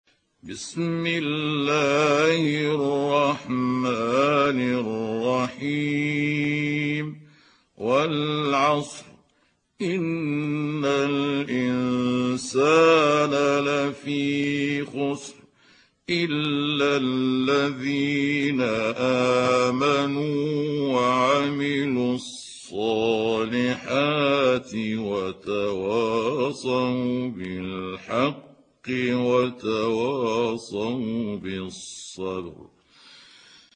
دانلود سوره العصر mp3 محمود عبد الحكم (روایت حفص)